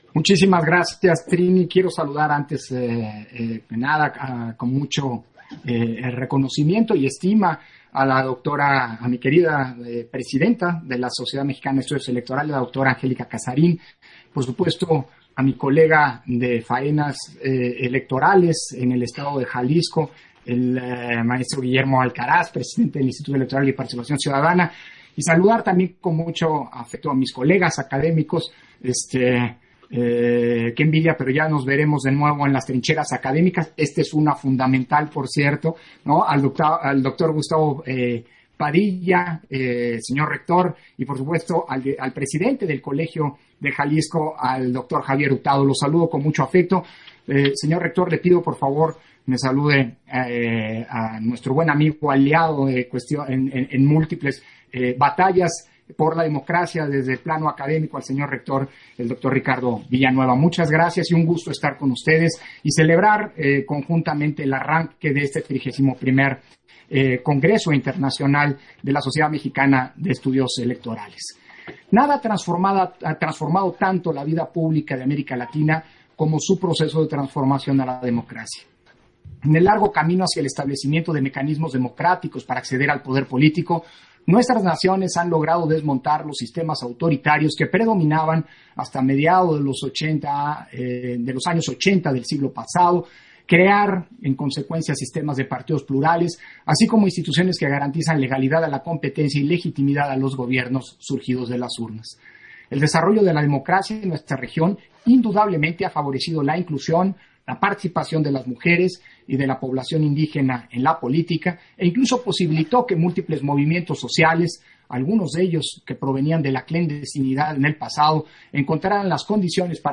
Intervención de Lorenzo Córdova, en la ceremonia de inauguración del XXXI Congreso Internacional de Estudios Electorales